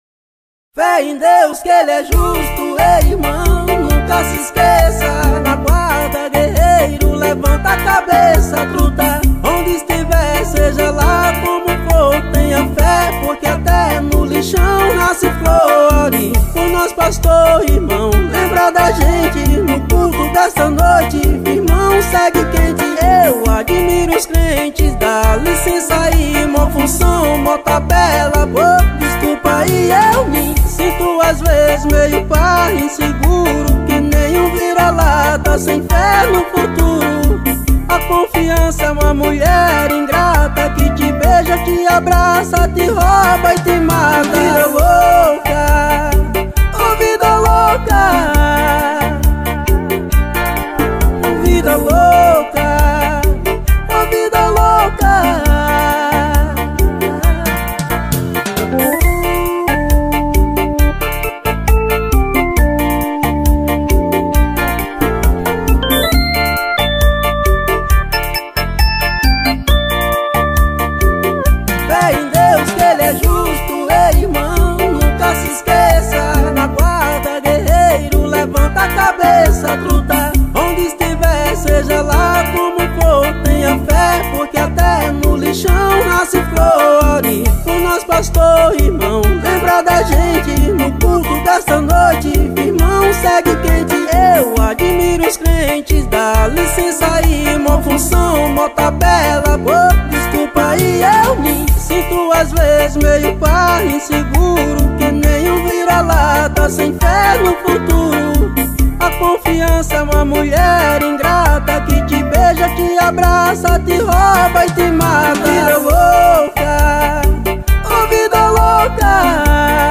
OUÇA NO YOUTUBE Labels: Arrocha Facebook Twitter